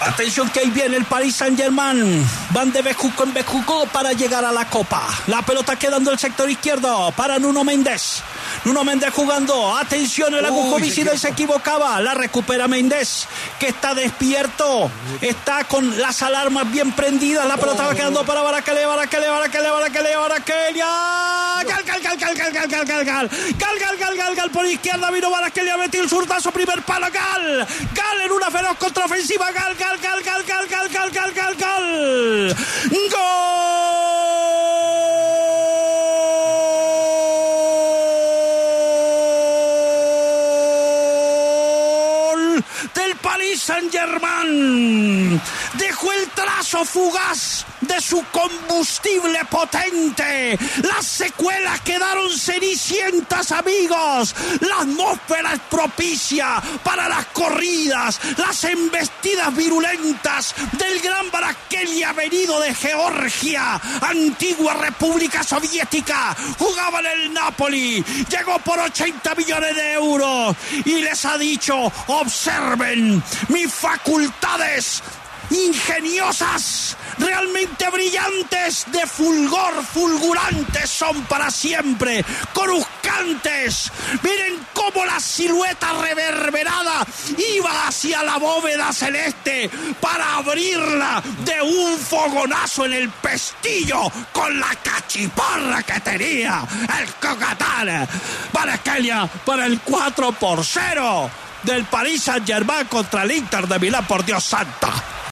“Dejó su trazo fugaz”: Martín De Francisco en su narración del golazo de Kvaratskhelia para el 4-0
Martín De Francisco se sorprendió con la goleada 4-0 del PSG al Inter de Milán en la final de la Champions League. Así narró el golazo de Khvicha Kvaratskhelia.